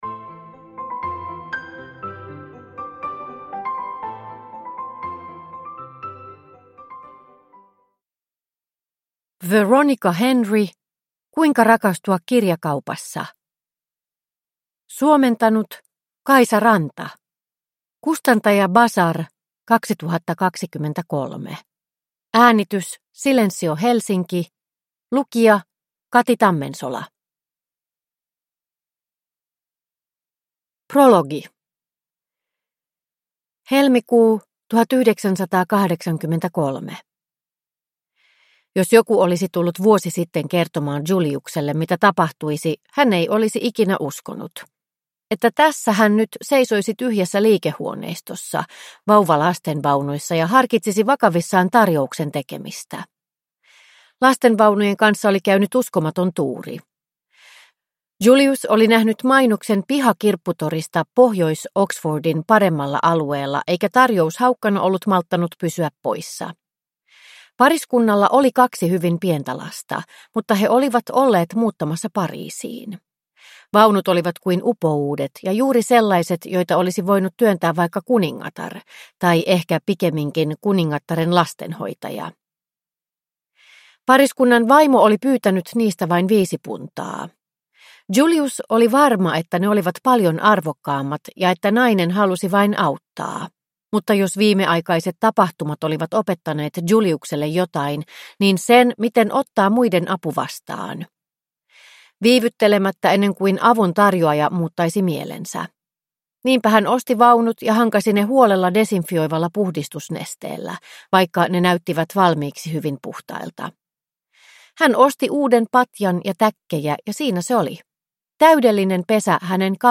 Kuinka rakastua kirjakaupassa – Ljudbok – Laddas ner